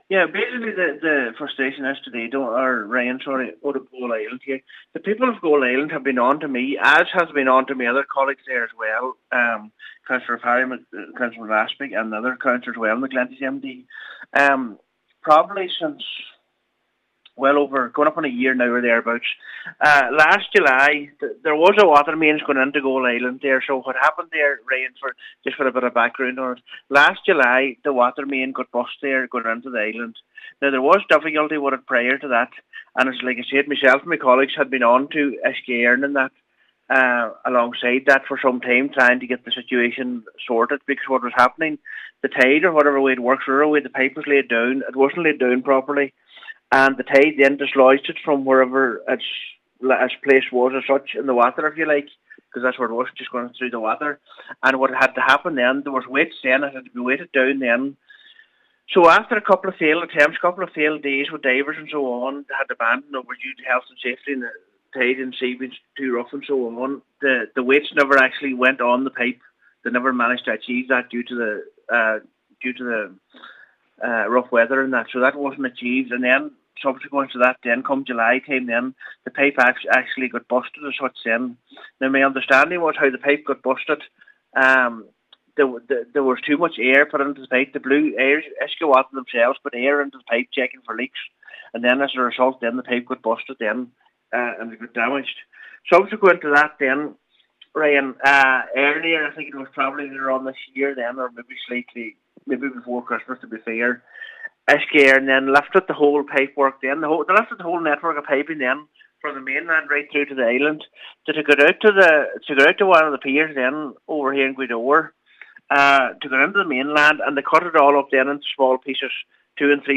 (Full Interview)